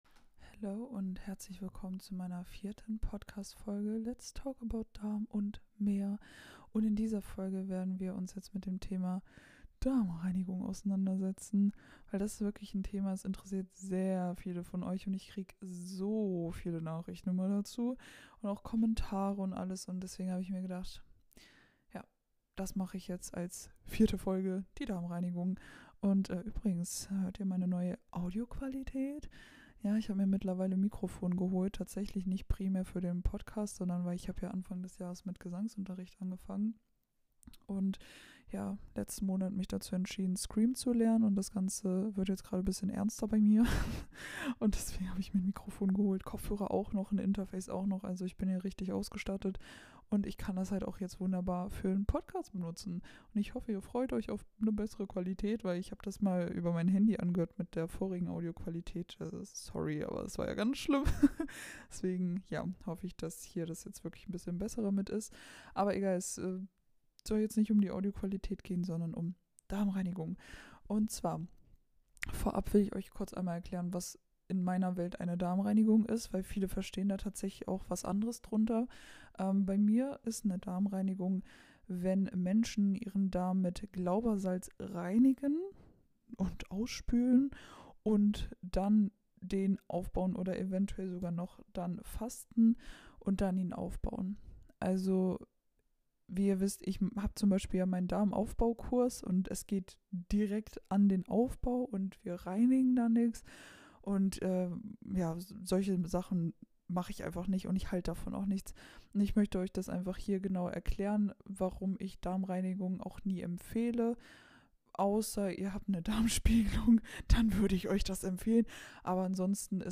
Wundert euch nicht, scheinbar habe ich mit meinem Mikrofon Probleme und irgendwie klingt meine Stimme dezent tief. Ich hoffe, dass ich das Problem bis zur nächsten Folge beheben kann und ihr euch nicht zu sehr an meine verzerrte Stimme stört.